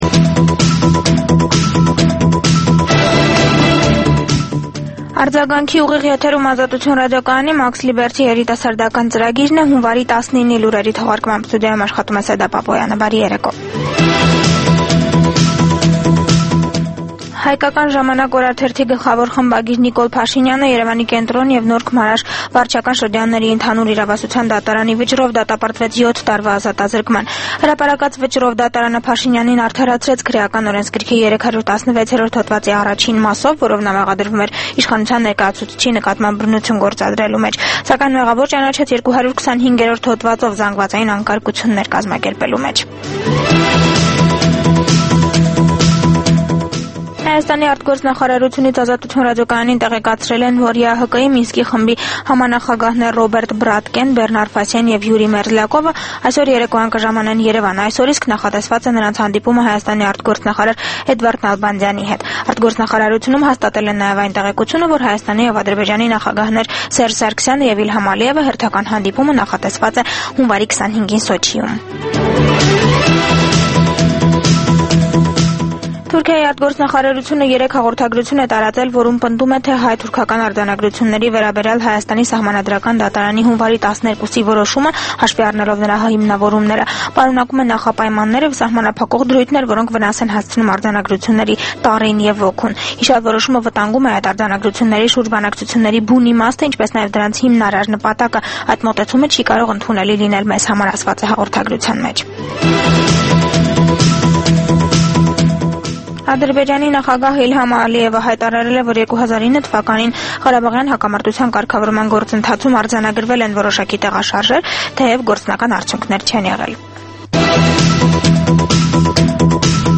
Լուրեր
Տեղական եւ միջազգային վերջին լուրերը ուղիղ եթերում: